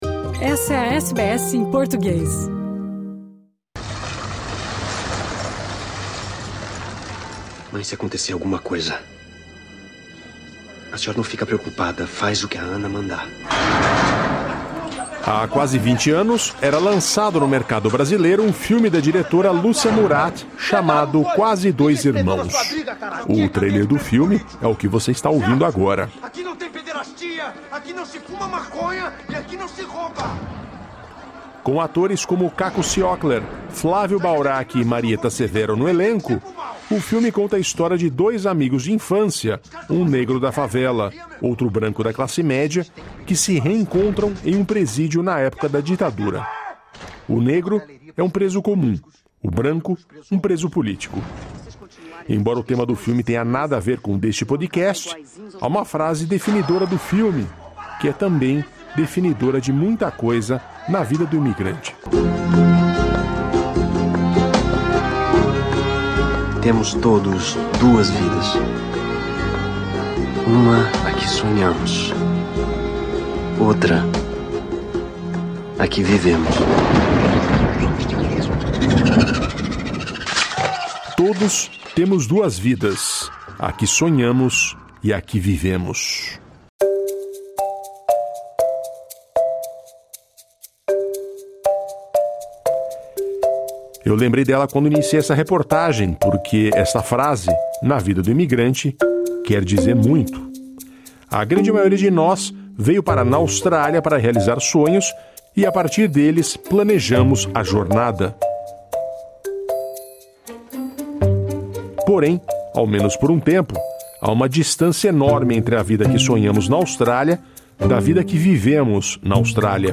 conversamos com duas profissionais de saúde mental brasileiras